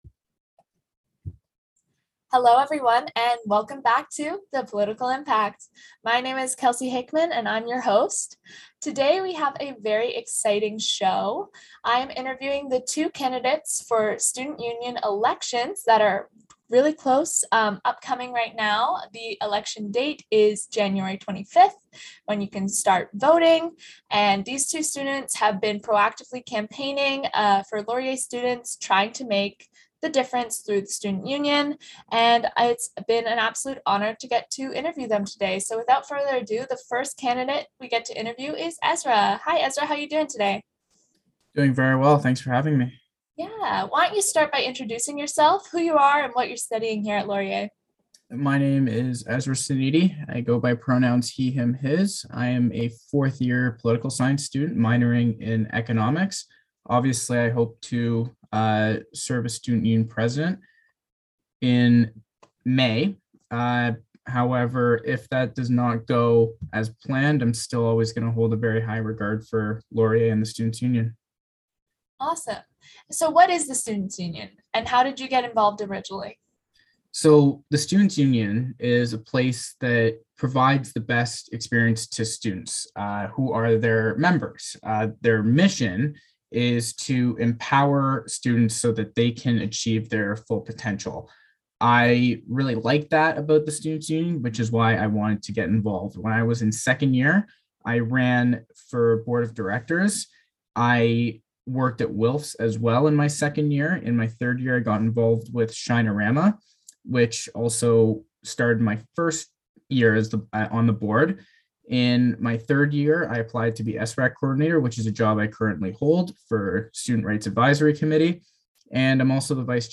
The Political Impact Ep. 7 - Student Union Elections: Interview